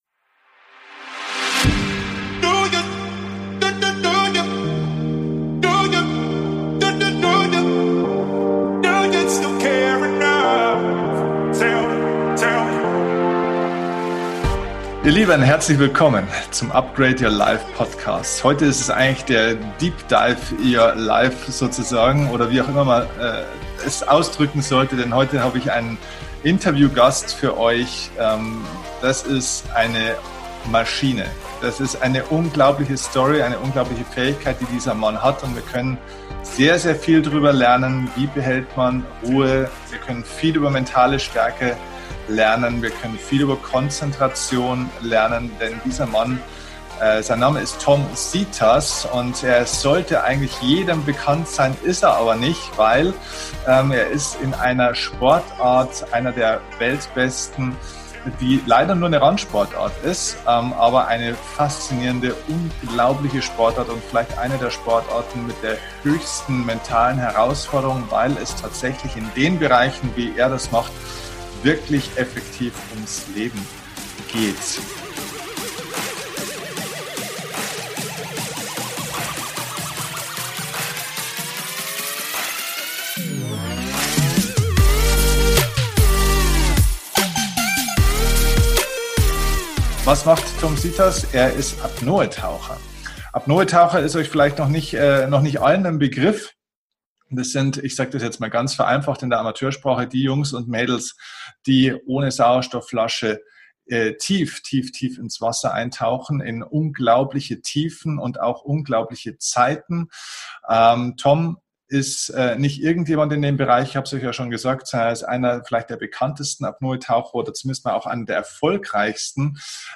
In unserem gemeinsamen Gespräch habe ich Ihn gefragt, wie das überhaupt möglich ist.